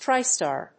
/ˈtraɪˌstɑr(米国英語), ˈtraɪˌstɑ:r(英国英語)/